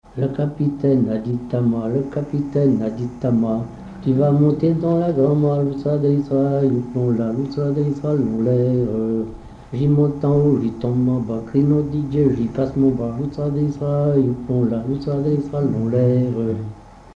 Localisation Cancale (Plus d'informations sur Wikipedia)
Catégorie Pièce musicale inédite